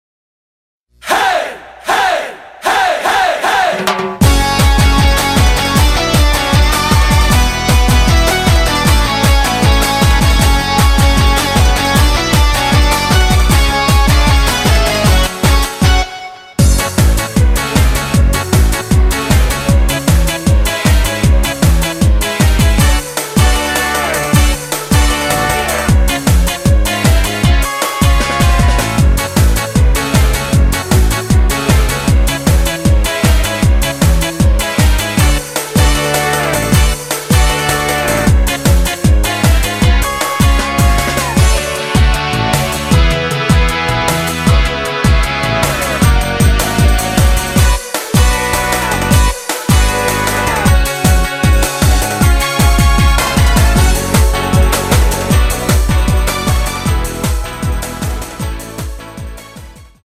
F#m
노래방에서 노래를 부르실때 노래 부분에 가이드 멜로디가 따라 나와서
앞부분30초, 뒷부분30초씩 편집해서 올려 드리고 있습니다.
중간에 음이 끈어지고 다시 나오는 이유는